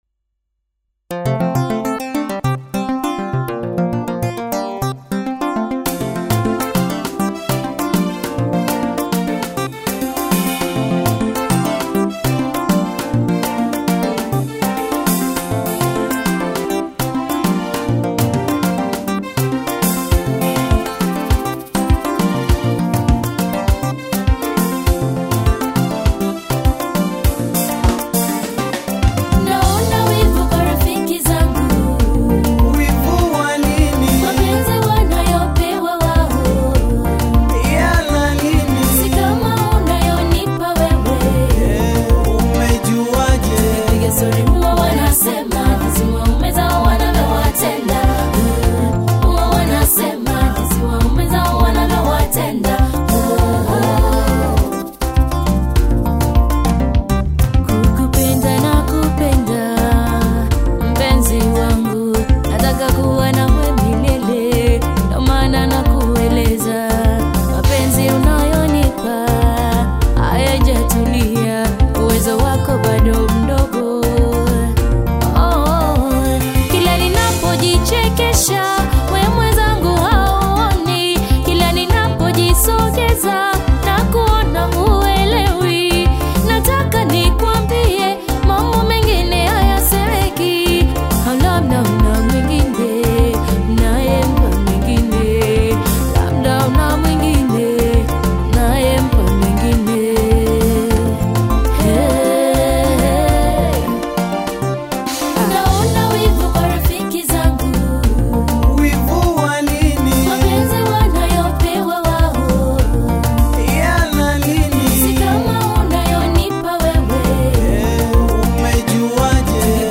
Bongo Fleva Kitambo